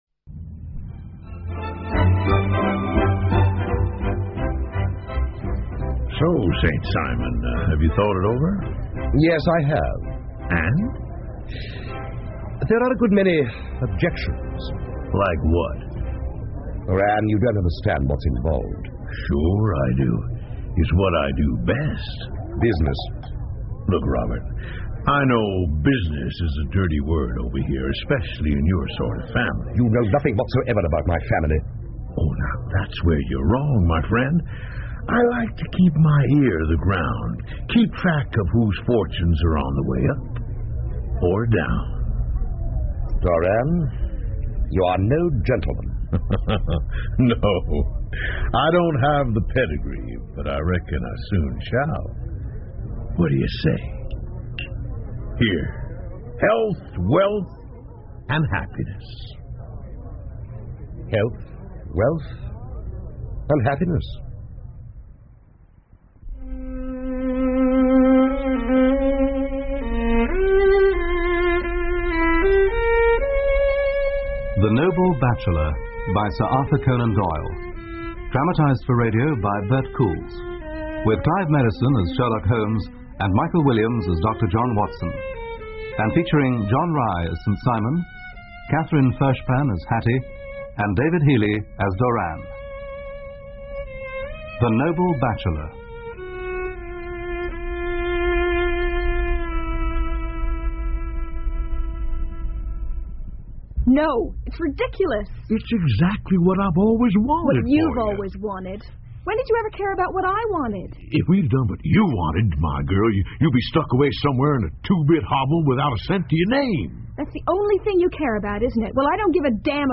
福尔摩斯广播剧 The Noble Bachelor 1 听力文件下载—在线英语听力室